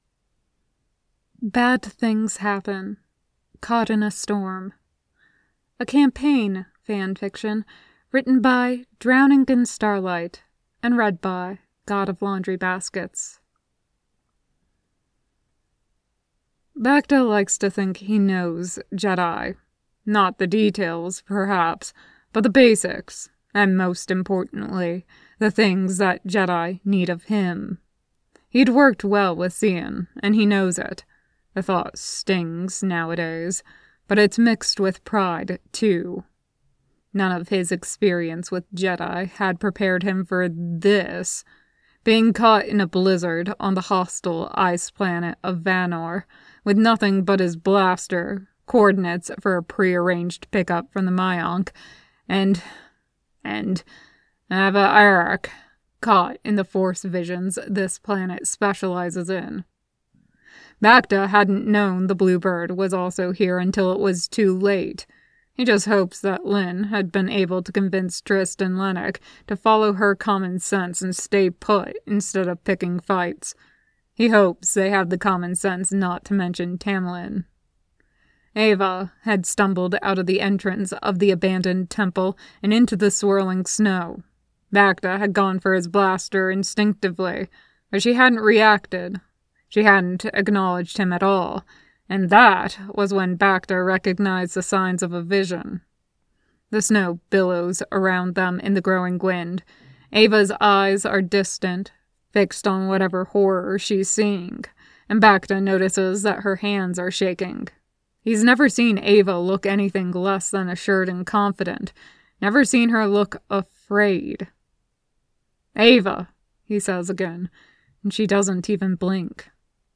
[Podfic] Caught in a Storm